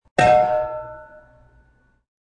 Descarga de Sonidos mp3 Gratis: campana 18.